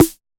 Index of /musicradar/retro-drum-machine-samples/Drums Hits/Tape Path A
RDM_TapeA_MT40-Snr01.wav